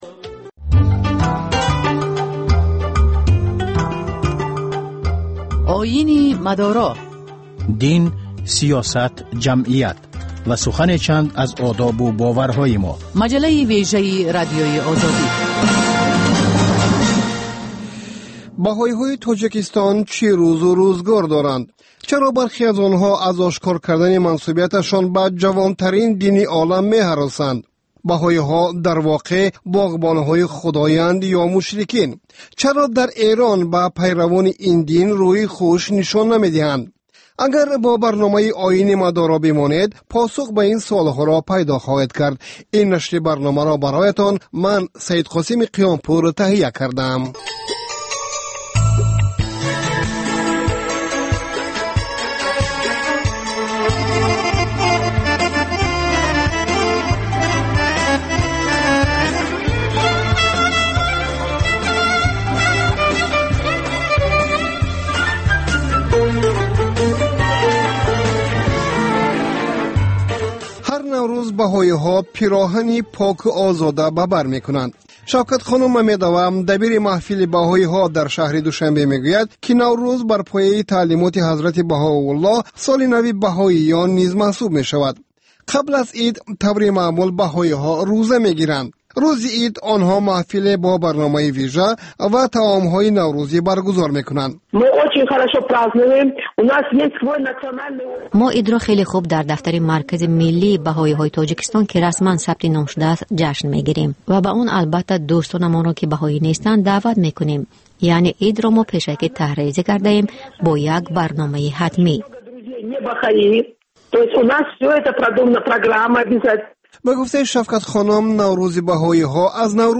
Дин ва ҷомеа. Гузориш, мусоҳиба, сӯҳбатҳои мизи гирд дар бораи муносибати давлат ва дин. Шарҳи фатво ва нукоти мазҳабӣ.